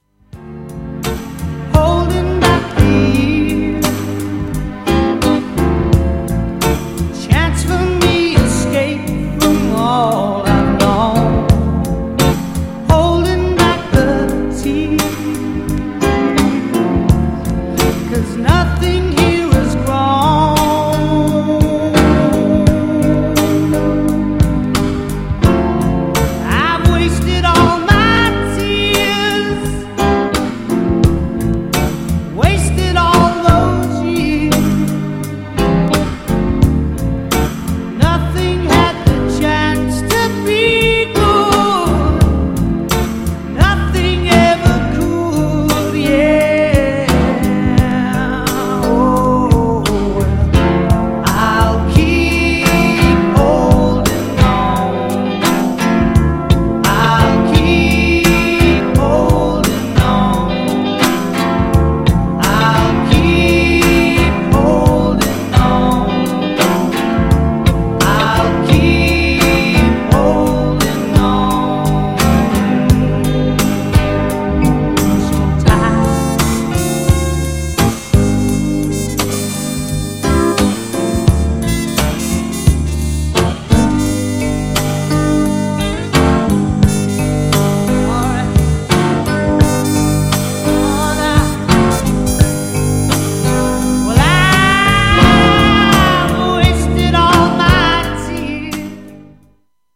GENRE Dance Classic
BPM 91〜95BPM